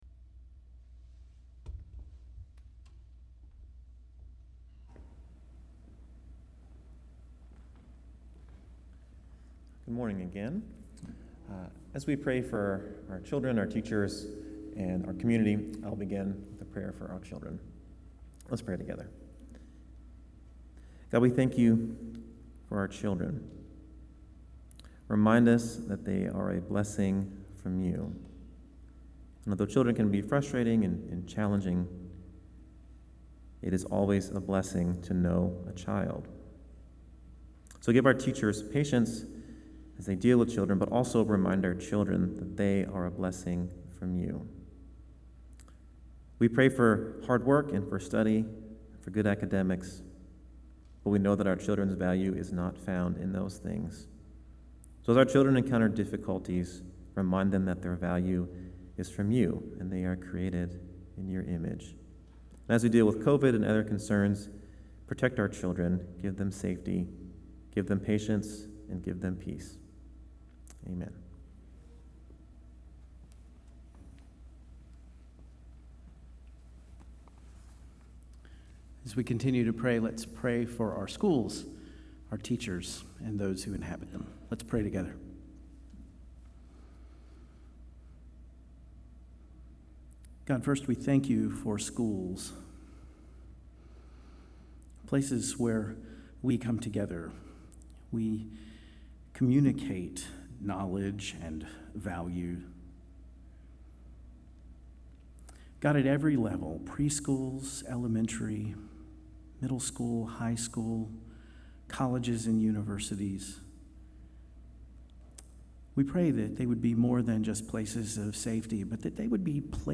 Passage: Nehemiah 10:28-29 Service Type: Traditional Service